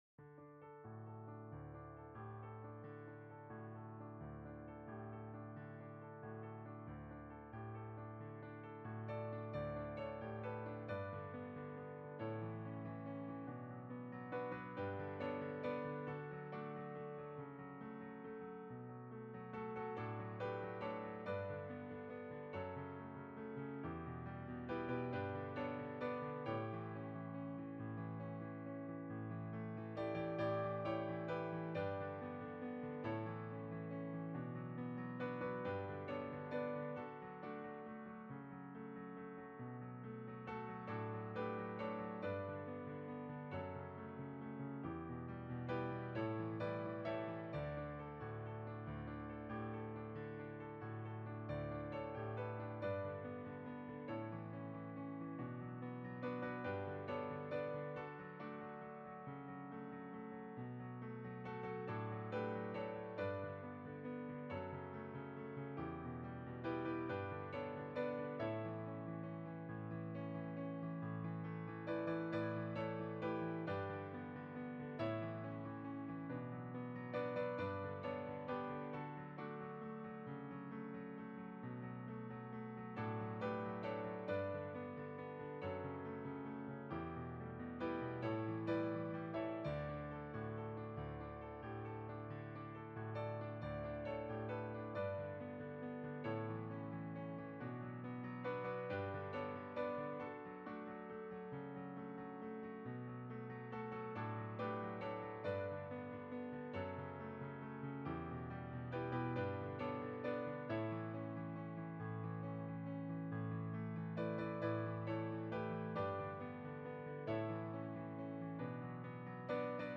version piano